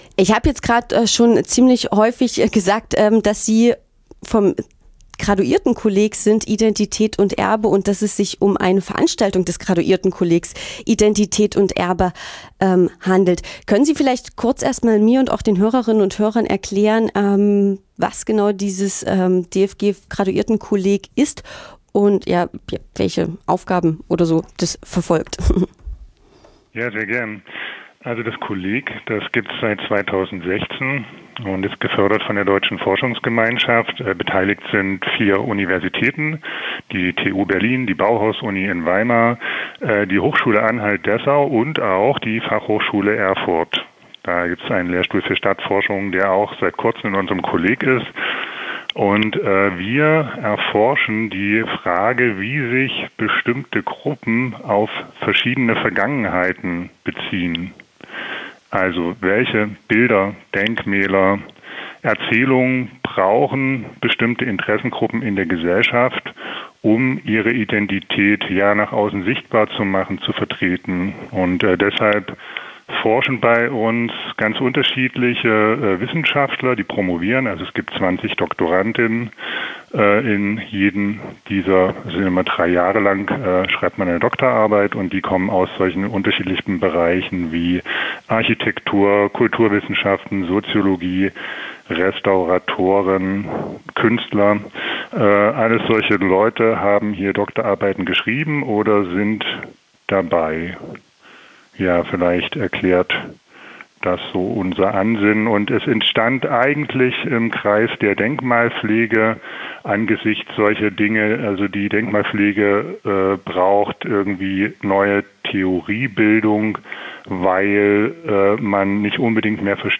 Censored? Conflicted Concepts of Cultural Heritage | Interview